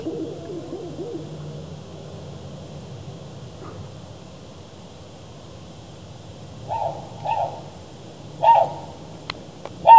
Сова в лесу